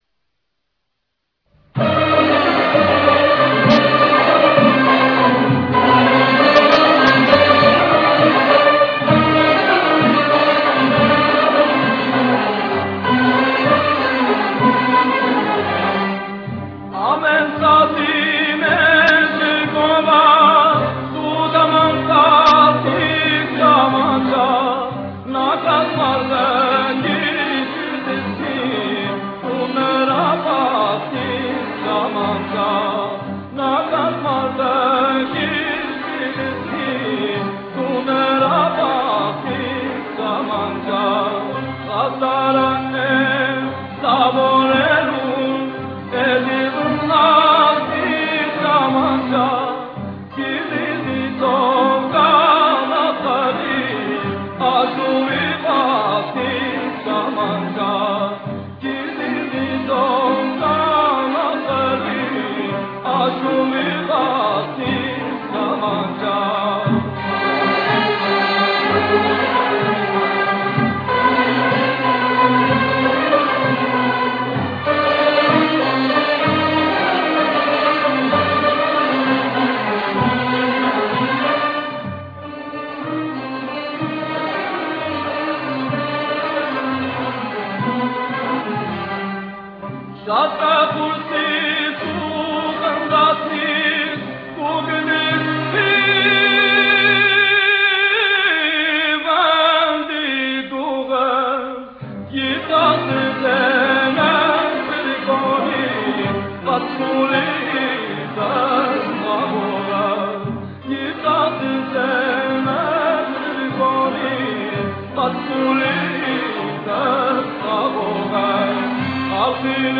Les Achoughs (Goussans)
Les contours mélodiques d'essence populaire, inspirés des chants paysans du Moyen Age, portent toujours la marque spécifique du musicien-improvisateur.
chant, Arménie Musique de tradition populaire et des achougs Ocora C 580005